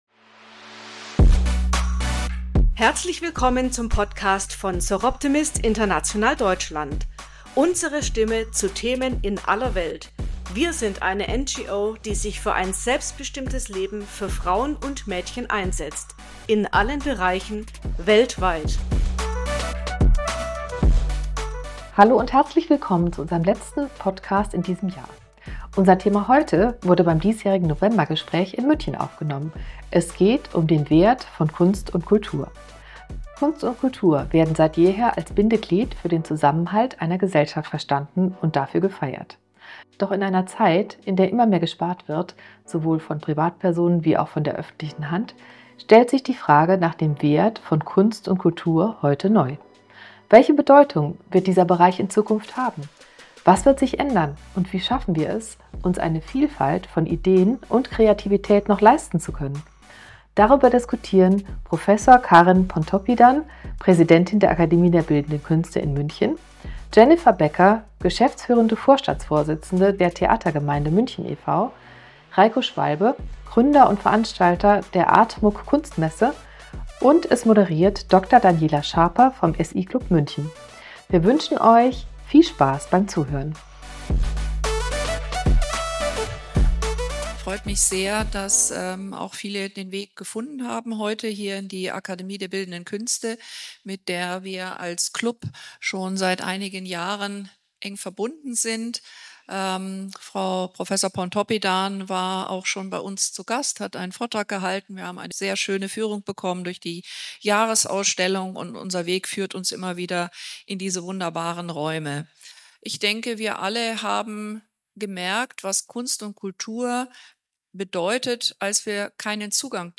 (Teil-Mitschnitt der Diskussionsrunde vom 20.11.2025)